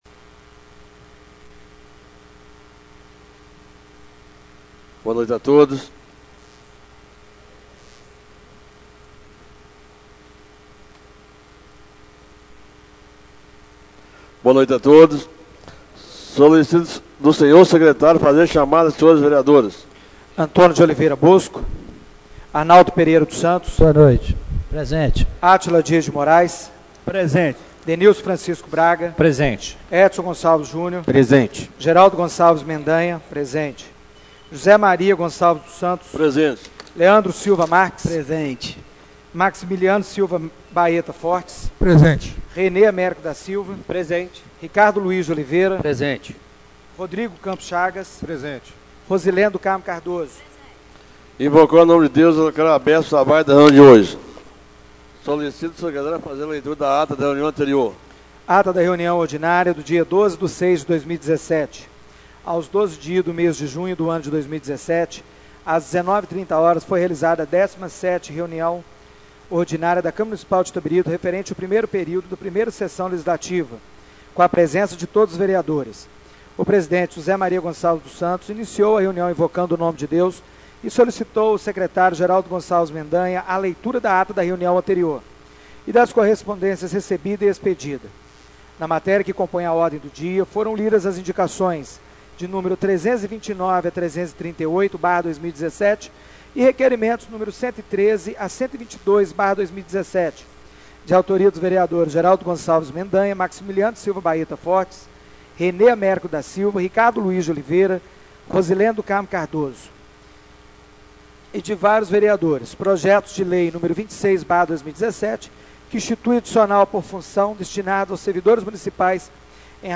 Reunião Ordinária do dia 19/06/2017